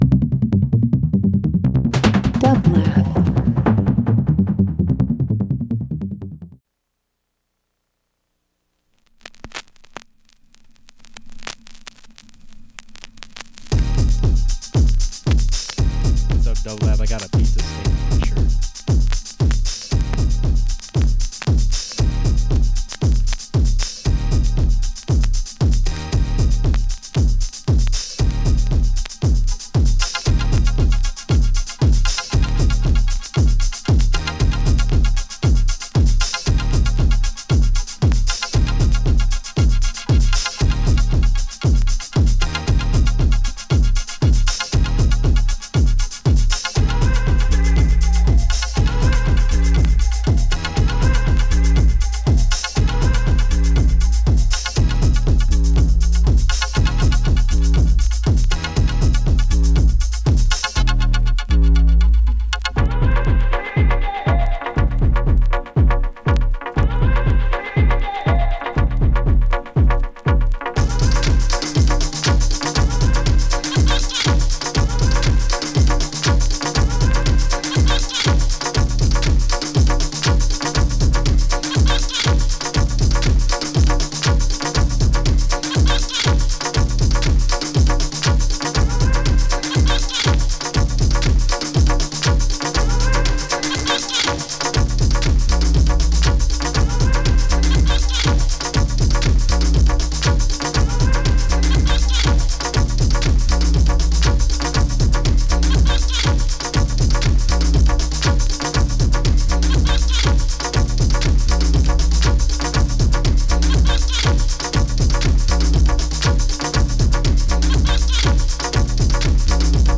Breaks Dance Tech House